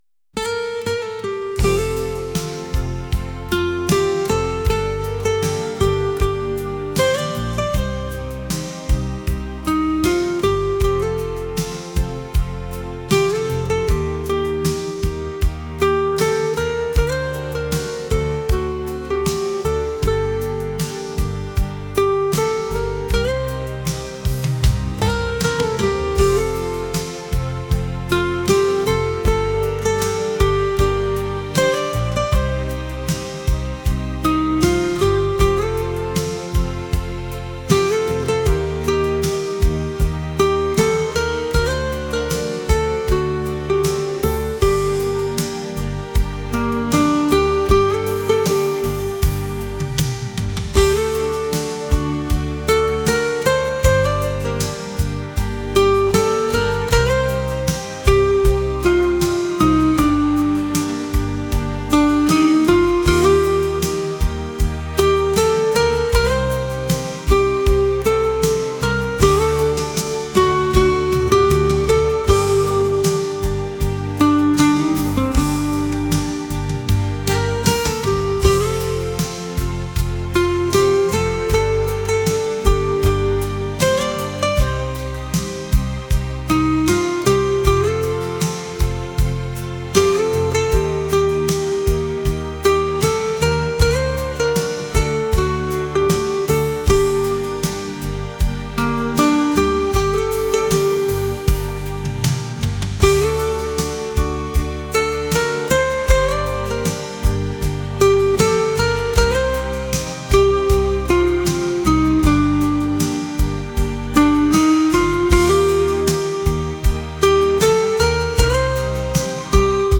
pop | acoustic | soul & rnb